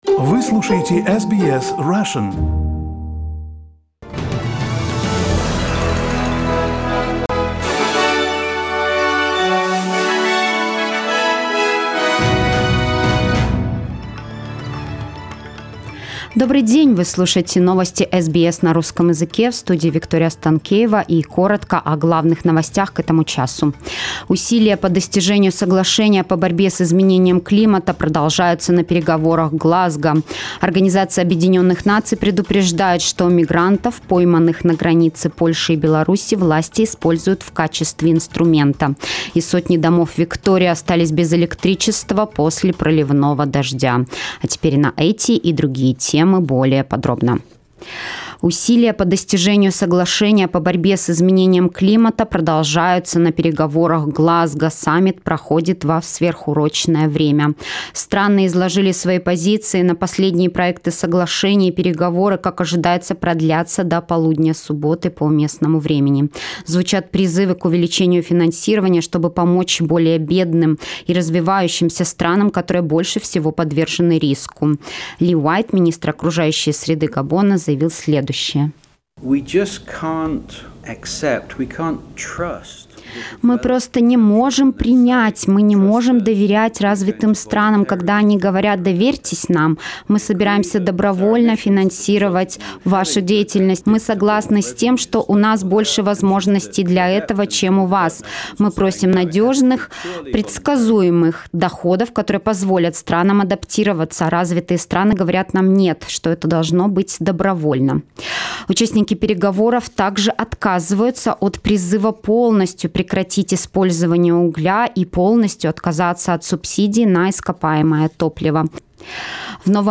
Listen to the latest news headlines in Australia from SBS Russian.